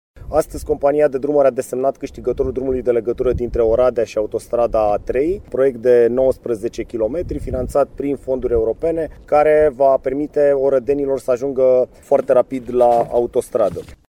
Anunțul a fost făcut, la Târgu Mureș, de Ministrul Transporturilor și Infrastucturii, Cătălin Drulă, care a vizitat un alt șantier al aceleiași companii: